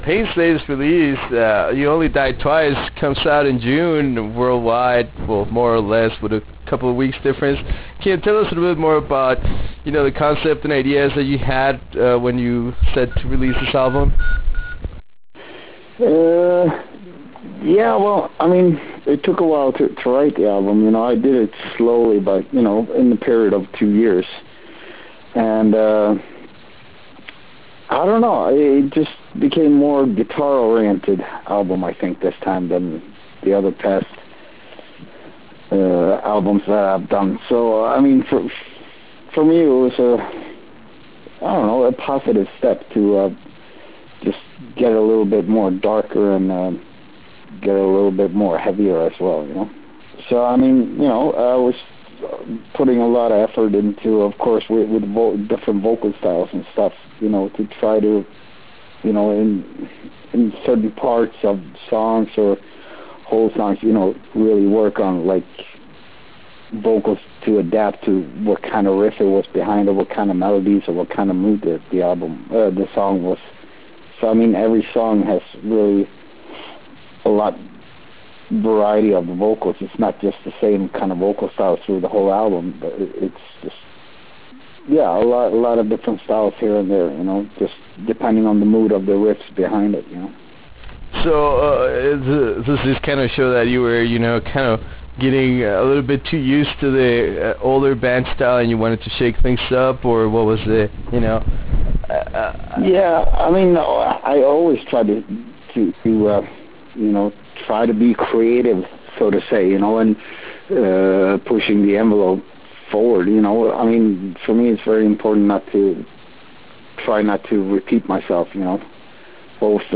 Interview with Peter Tägtgren of Pain